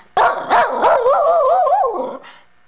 WooWoo
woo2.wav